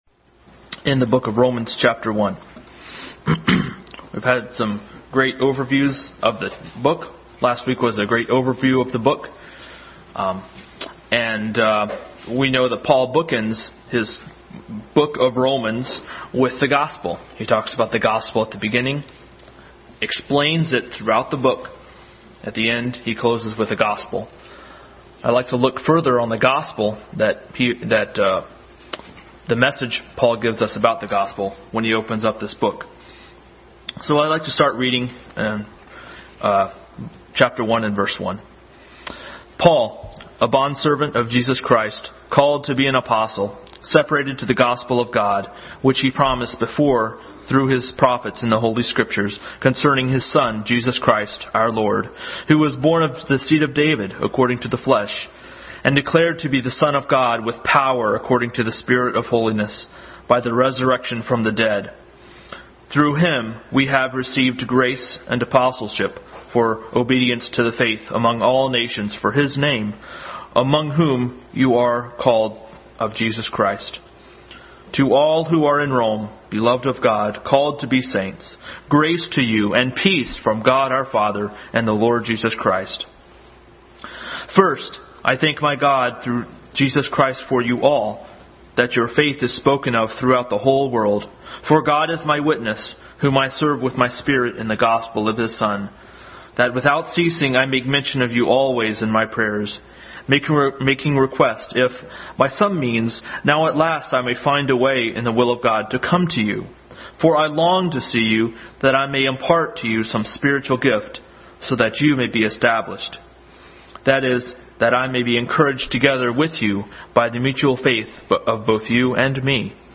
Adult Sunday School Class continued study in the book of Romans.
3:21-26 Service Type: Sunday School Adult Sunday School Class continued study in the book of Romans.